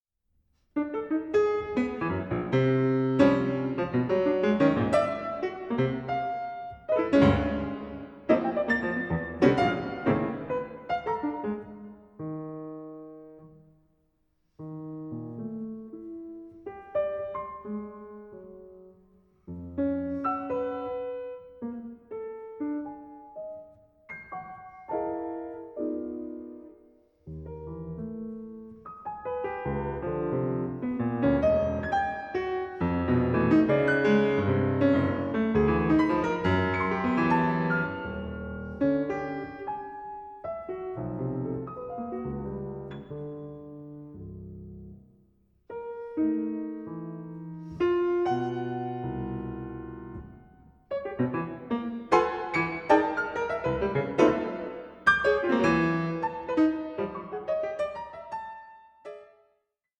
Modéré 2:39